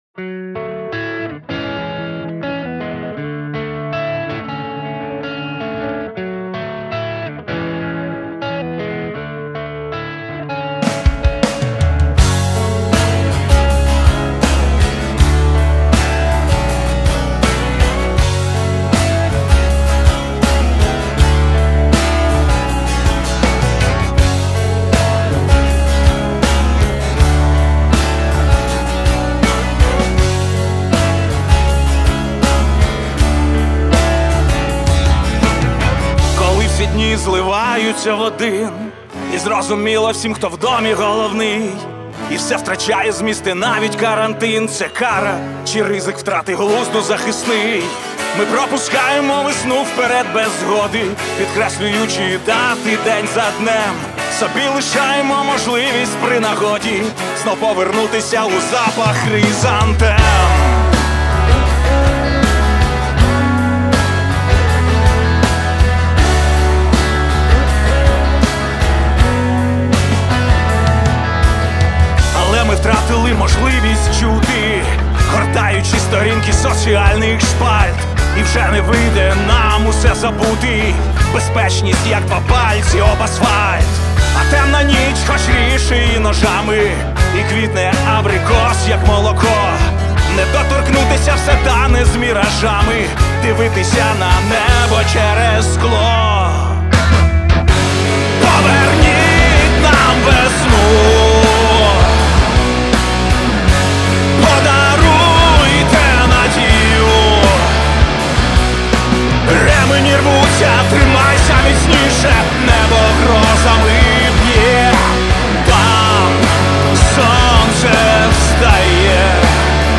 • Жанр: Pop, Rock